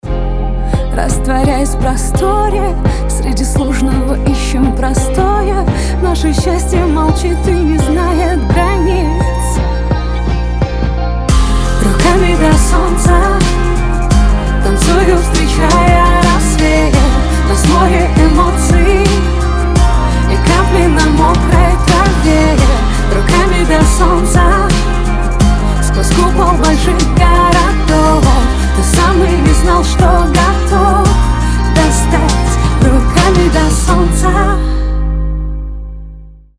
• Качество: 320, Stereo
лирика
Хип-хоп
романтичные
теплые
трогательные